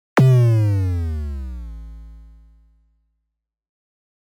알림음 8_Blast6.mp3